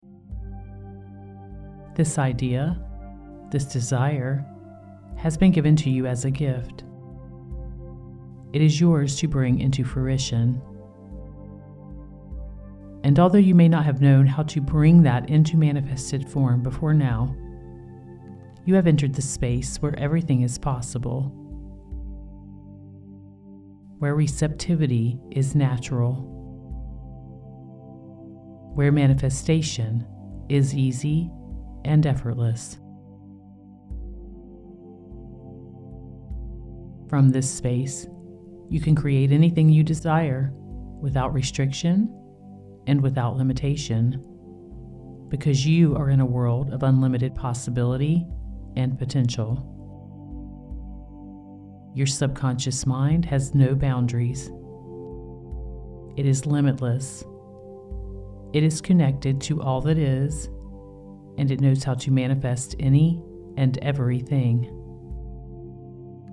This is a theta track that will slow your brain waves down, so please do not drive or operate heavy machinery while listening!
Guided-Hypnosis-Sample.mp3